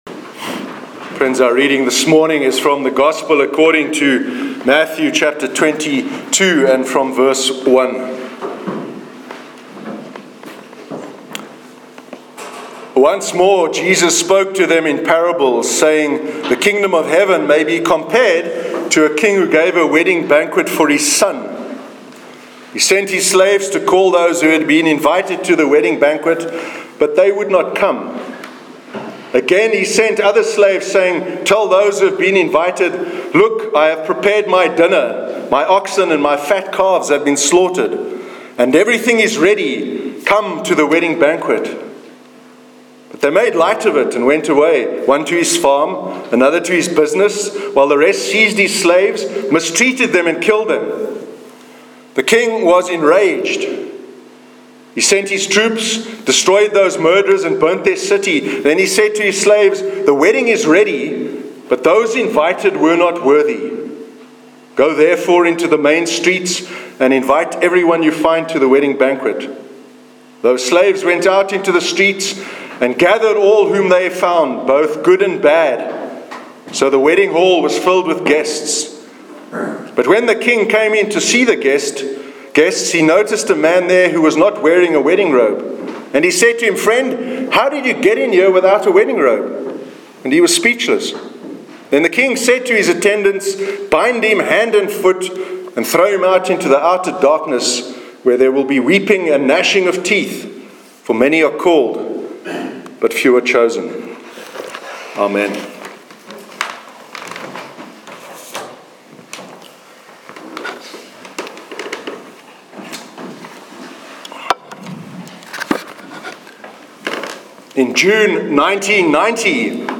Sermon on the Parable of the Wedding Banquet